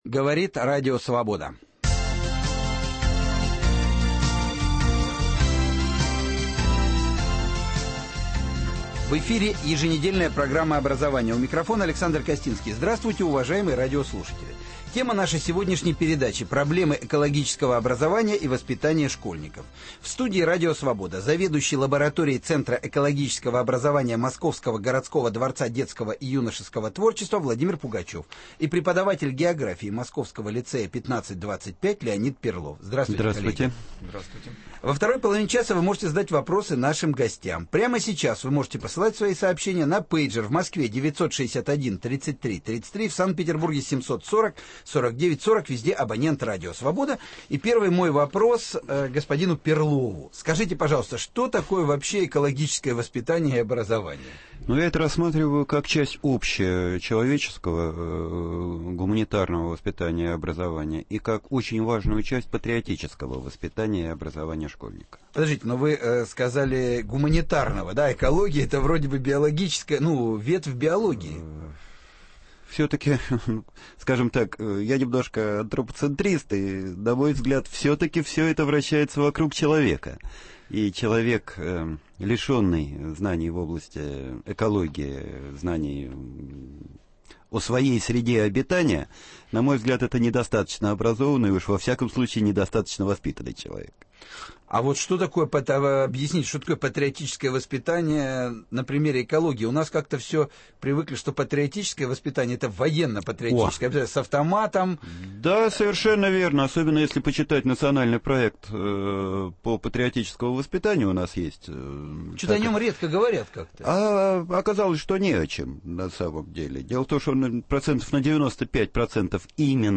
Проблемы экологического образования и воспитания школьников. Гости студии